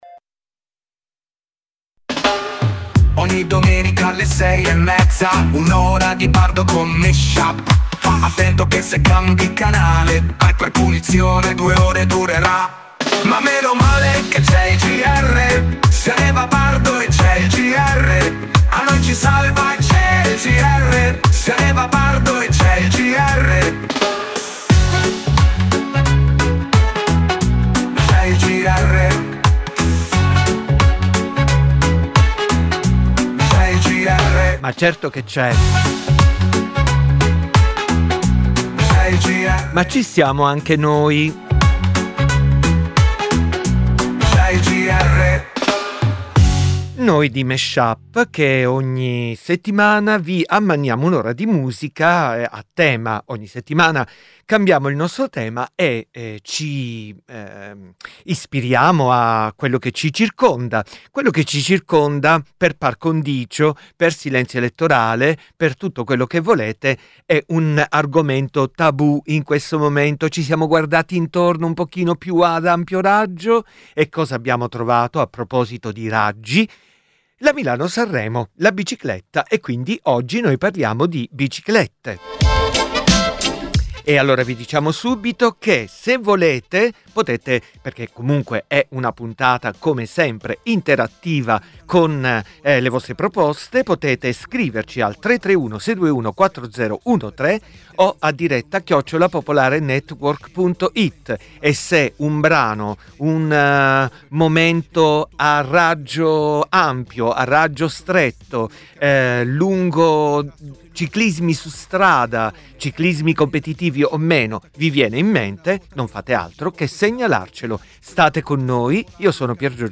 Musica che si piglia perché non si somiglia.